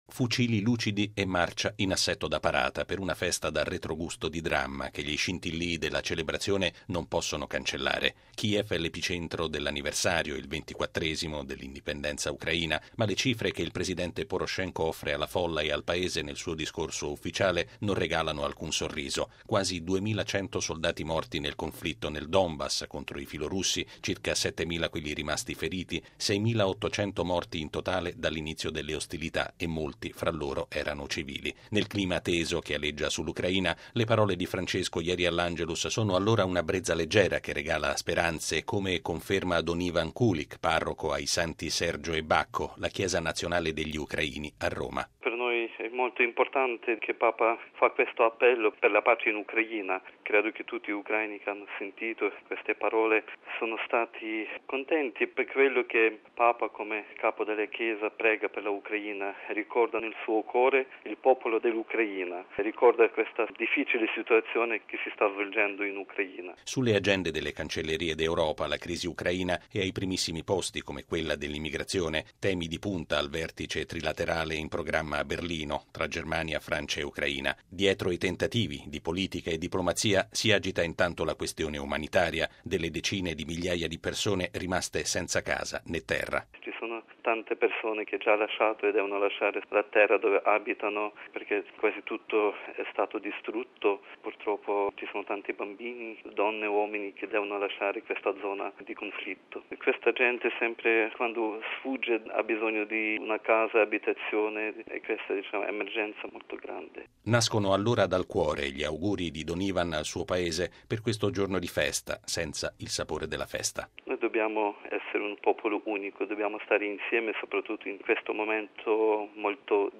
Gli auspici del Papa – che rinnova anche la “vicinanza spirituale alle vittime, alle loro famiglie e a tutti coloro che soffrono” – seguono l’appello di pace per l’Ucraina levato ieri all’Angelus, perché cessi un conflitto che ha fatto migliaia di morti, feriti, sfollati. Il servizio